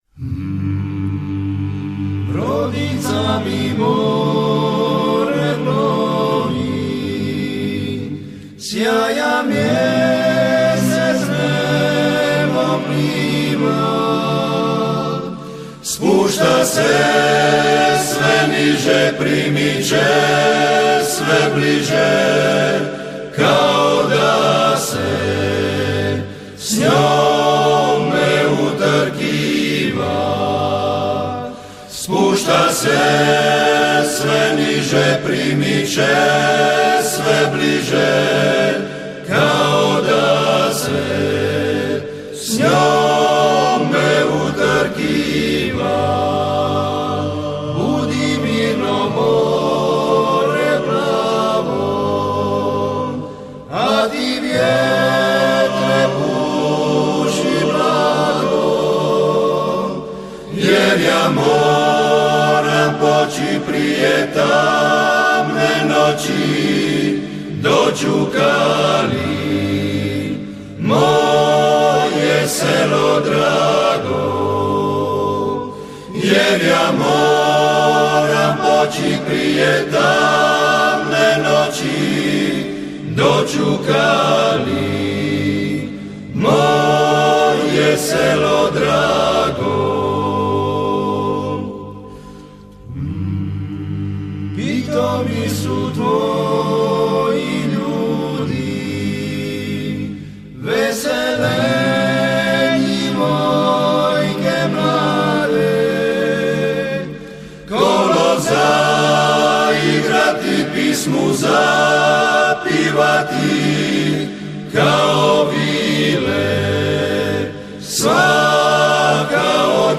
I. Tenor
Bariton
I. Bas